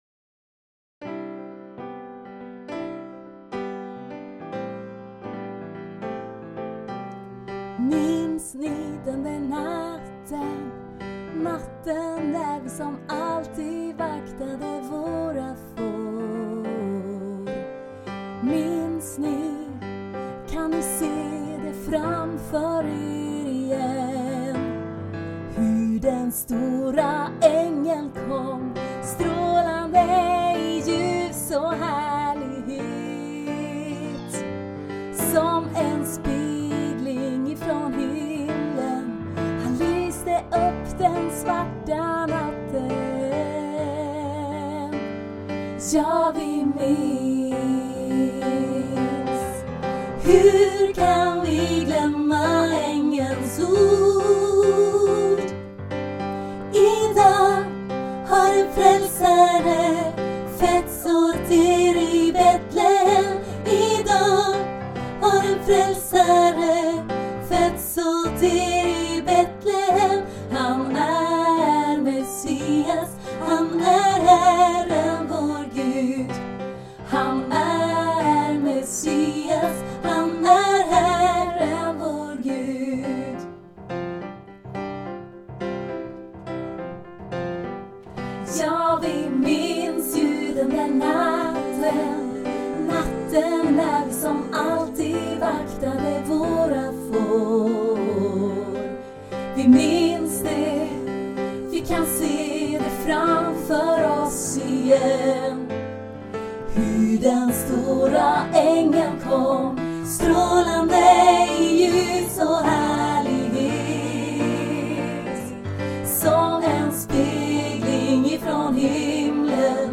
För solist (herde) och för kör av herdar i olika åldrar.